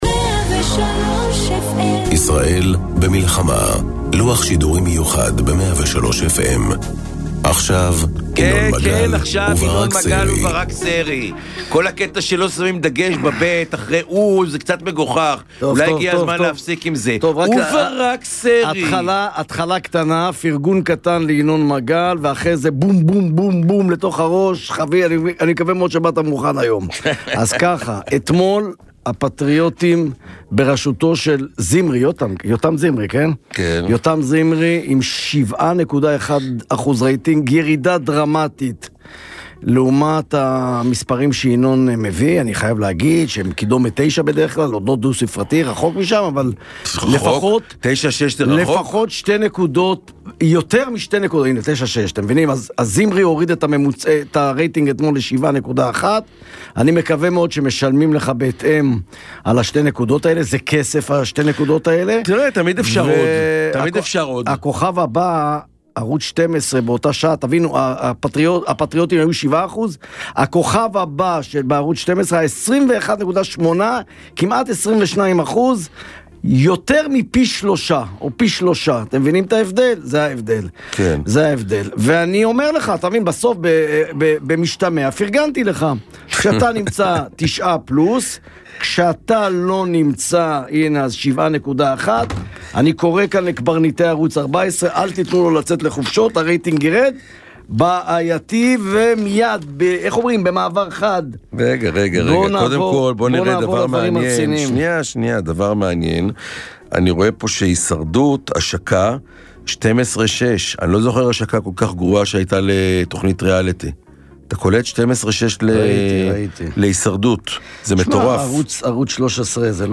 בכל יום, פותחים ינון מגל ובן כספית את המיקרופון בשעה 09:00 ועד לשעה 11:00 לא מפסיקים לדון ולהתווכח על כל הנושאים הבוערים שעל סדר היום. בין אם זה כלכלה, ביטחון, פוליטיקה, חברה ואפילו ספורט - אין נושא שלשניים אין מה להגיד לגביו. כאן תמצאו את המרואיינים המעניינים והמסקרנים ביותר שהם חלק משיחת היום - אל תדאגו, יש מקום לכולם ולמגוון דעות.